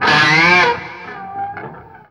DIVEBOMB 5-L.wav